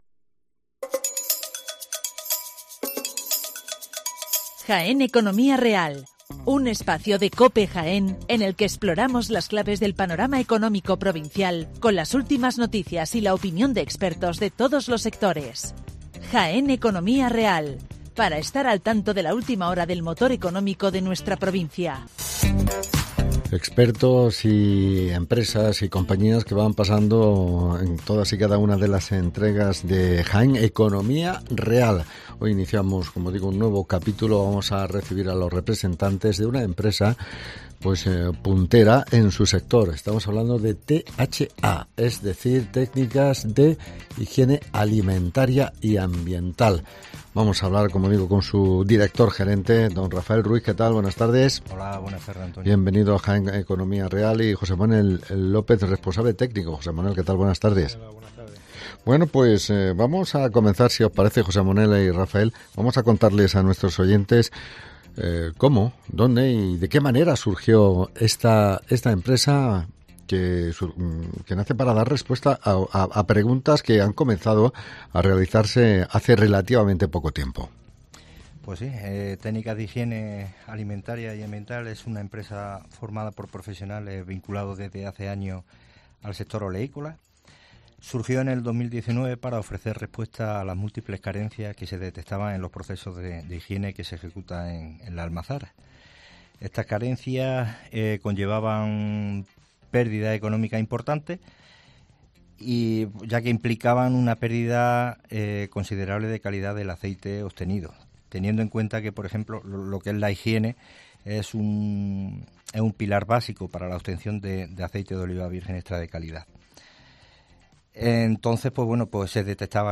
Entrevista con THA. Higiene Alimentaria